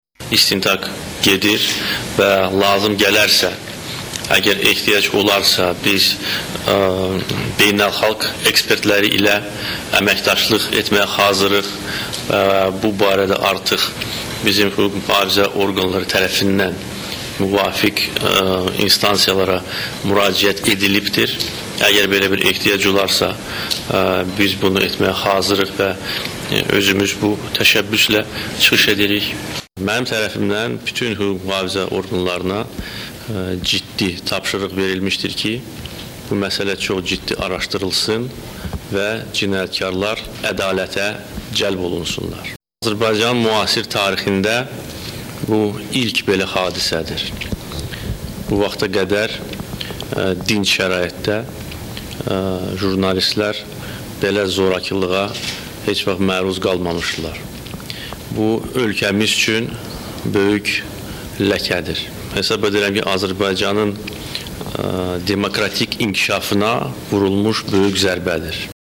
Hadisədən dərha sonra çıxış edən Azərbaycan prezidenti bildirdi ki, hüquq-mühaqfizə orqanları cinayətin açılamsı üçün hər şey edəcək və ehtiyac olsa Azərbaycan xarici ölkələrdən peşəkarlar da dəvət edəcək